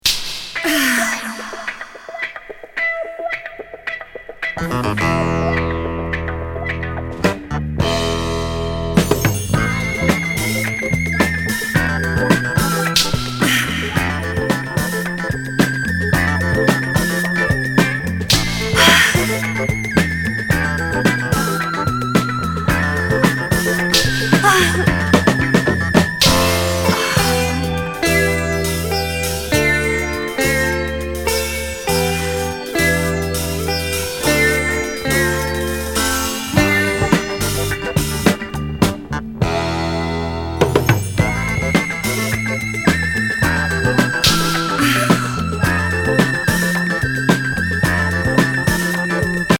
妖艶ウィスパー・ボイス入り